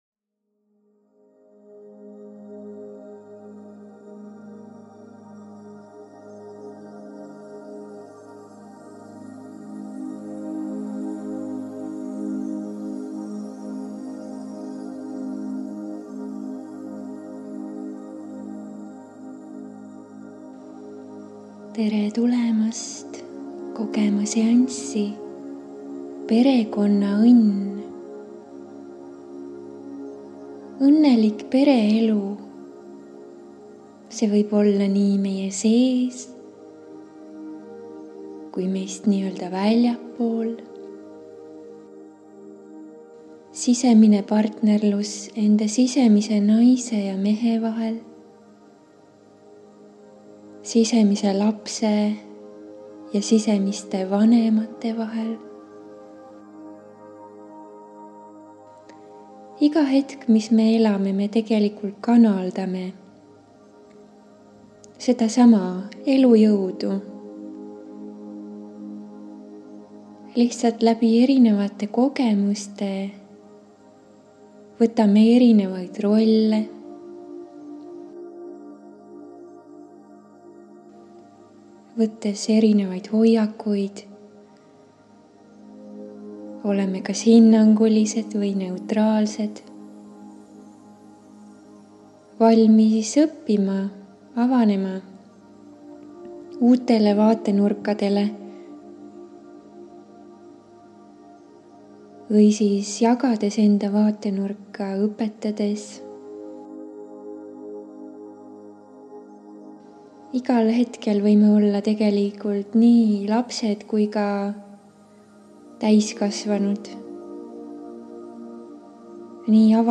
MEDITATSIOON